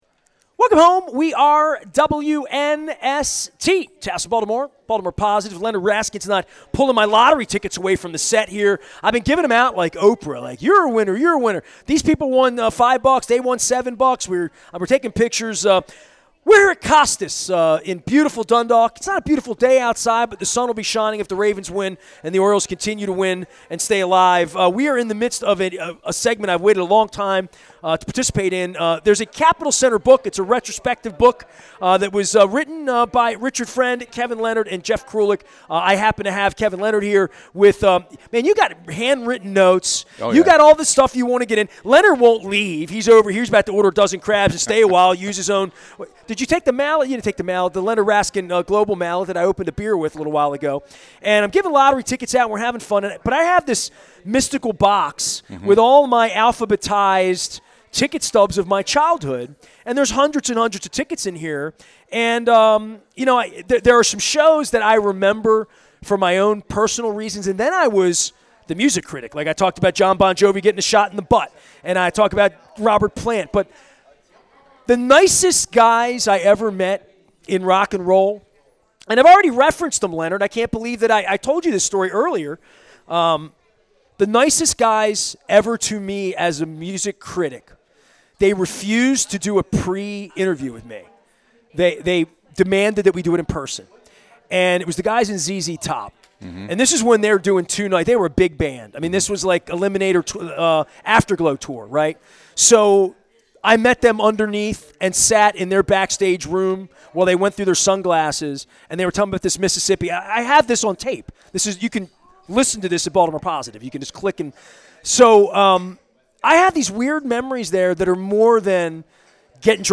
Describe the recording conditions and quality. at Costas Inn in Dundalk on the Maryland Crab Cake Tour. Interviewing ZZ Top, meeting James Brown and getting a backstage tour from Jon Bon Jovi.